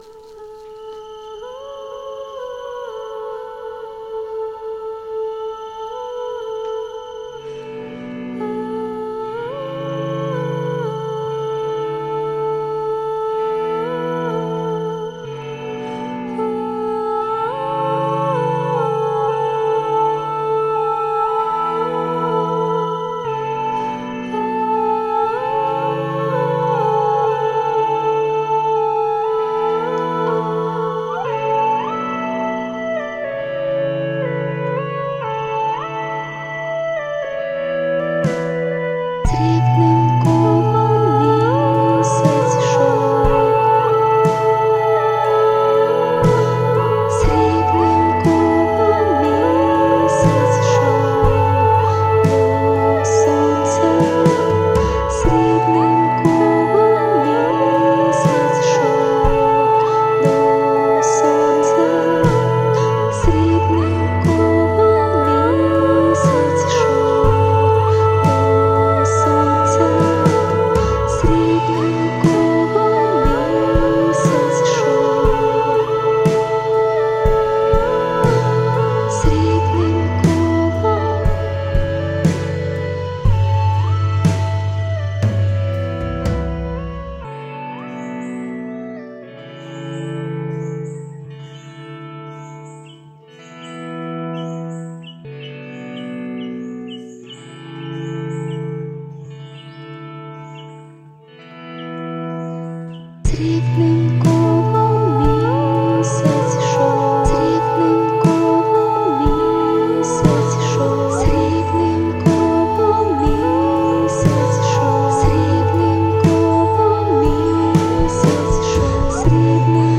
experimental electronica/downtempo